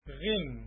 - En haut de la gorge, sortent le ghain (
Par contre le Ghin () et le Kha () doivent être emphatisés.
ghin.mp3